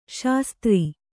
♪ śastri